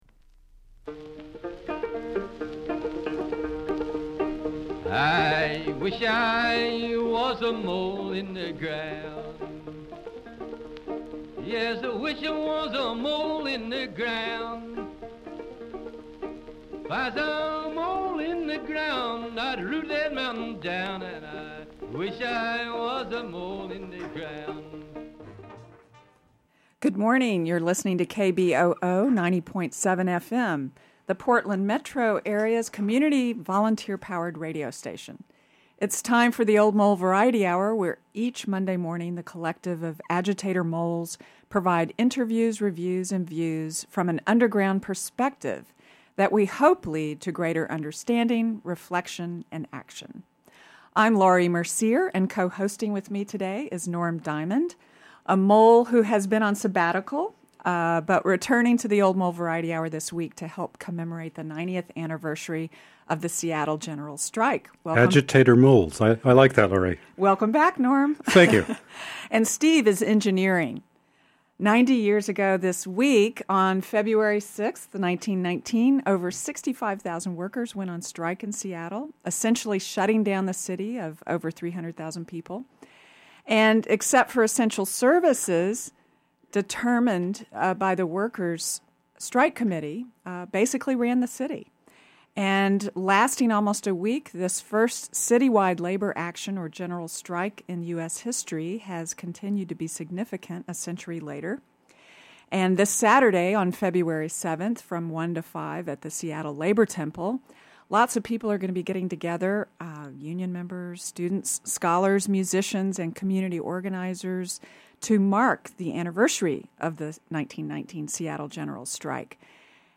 Seattle's General Strike of 1919: music, history, and lessons for today. 2. Movie Review: Switch: A Community in Transition play pause mute unmute KBOO